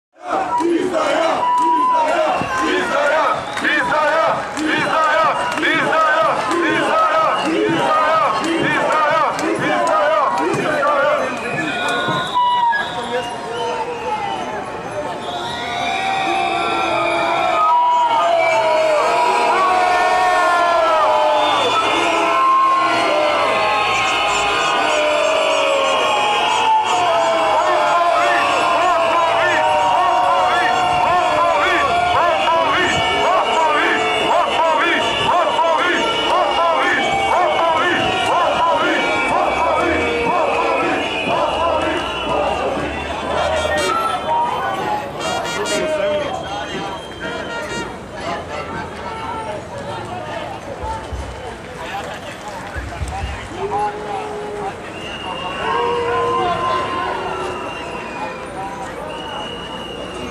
Protest protiv Beograda na vodi (atmosfera)